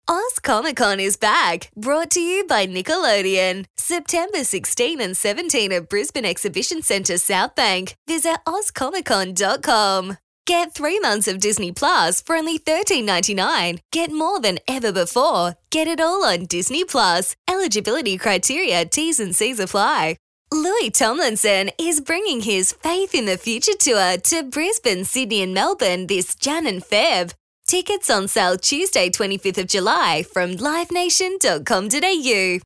• Promo
• Young
• Bright
• Fresh & Friendly
• Neumann TLM 103
• Own Home Studio